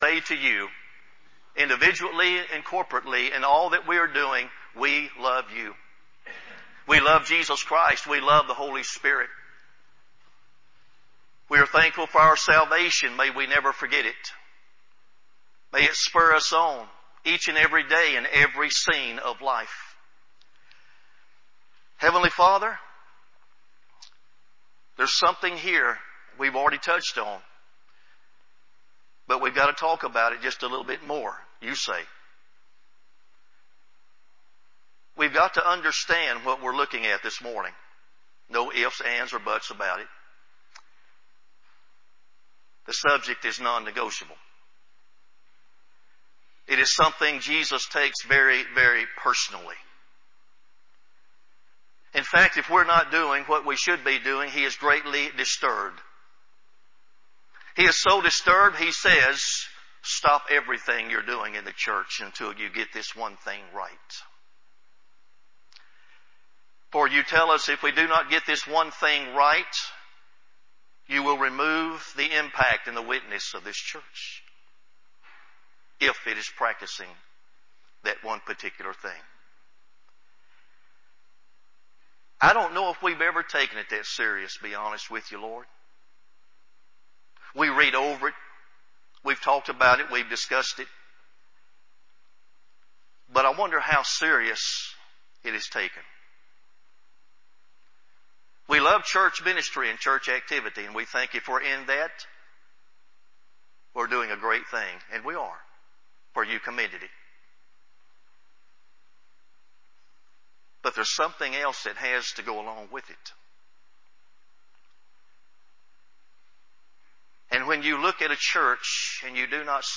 sermon-10-27-CD.mp3